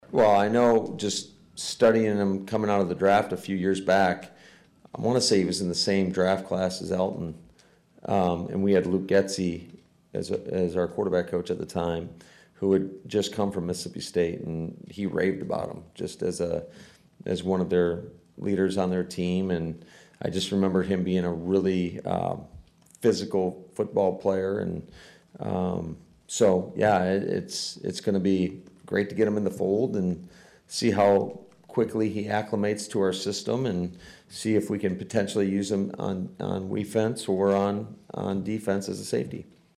Head Coach Matt LaFleur sounded happy to get him.